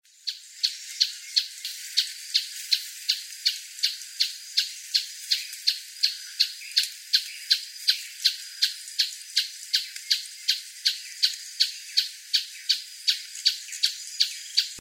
Planalto Tapaculo (Scytalopus pachecoi)
Se lo veía fugazmente,se acercaba y alejaba
Location or protected area: Reserva Privada San Sebastián de la Selva
Condition: Wild
Certainty: Recorded vocal